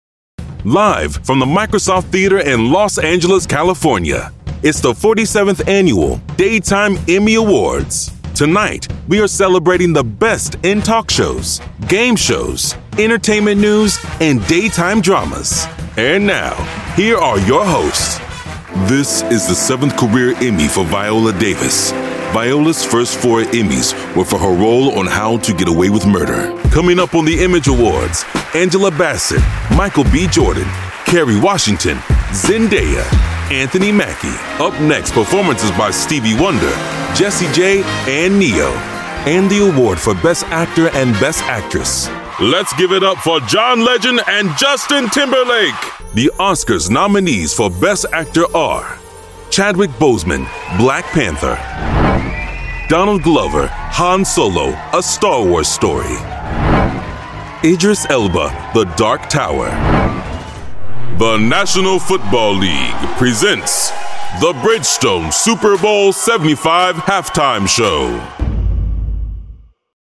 Deep Voice, Powerful, Epic
Live Announce